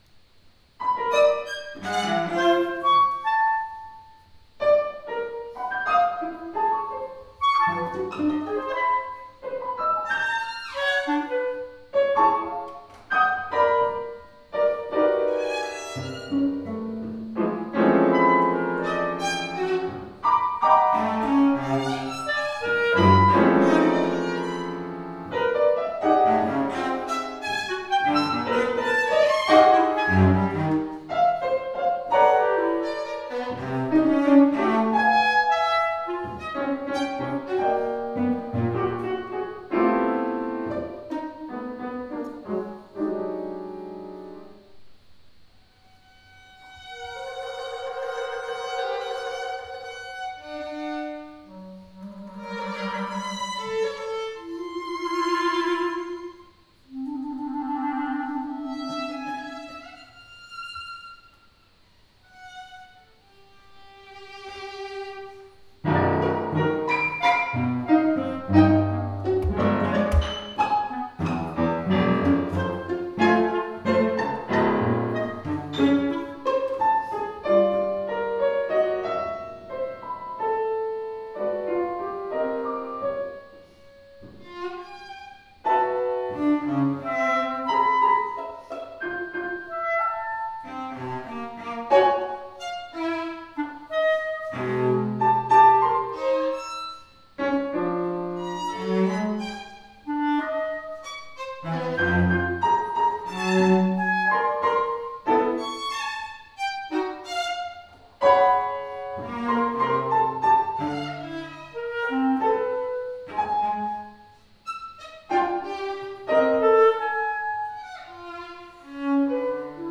Kwartet in memoriam Anton Webern na klarnet, skrzypce, wiolonczelę i fortepian (2025) to rodzaj wariacji, których tematem jest dziesięć początkowych taktów Koncertu na 9 instrumentów op. 24 Antona Weberna.
Składa się ona z czterech grup 3-dźwiękowych, w których występują tylko dwa interwały – nona mała i tercja wielka.
Anton Webern, Kwartet in memoriam Anton Webern, współcześni kompozytorzy polscy, współczesne utwory kameralne, postmodernizm, technika dodekafoniczna